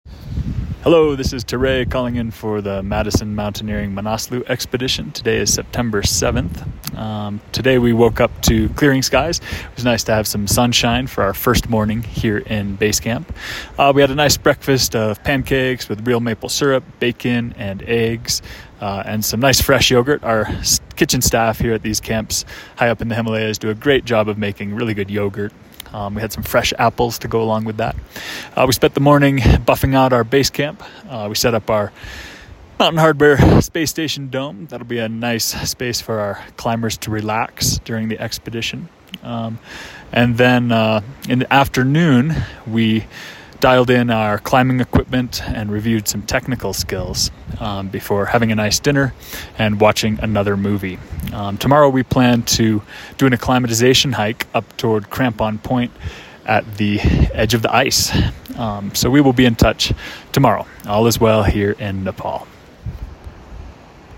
checks in with this dispatch from the Himalaya